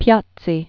(pyôtsē), Hester Lynch Also known as "Mrs. Thrale" (thrāl) 1741-1821.